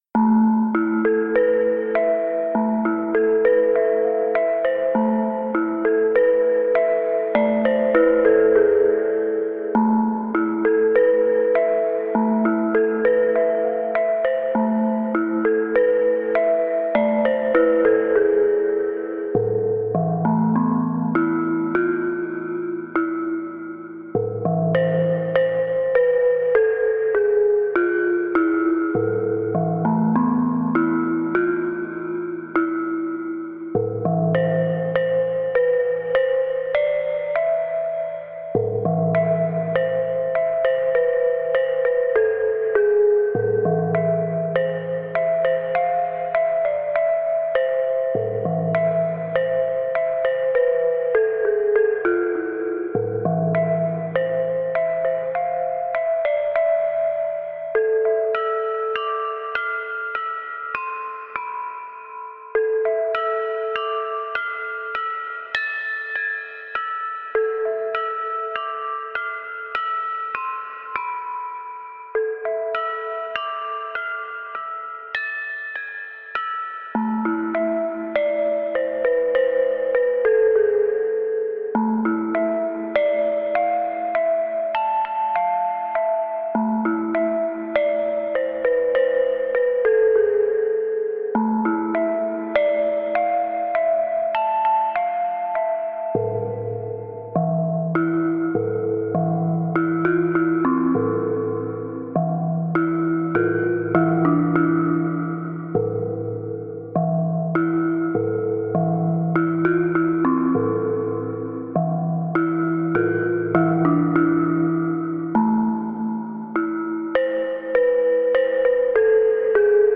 不思議で不気味な雰囲気の曲です。【BPM100】